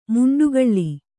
♪ muṇḍugaḷḷi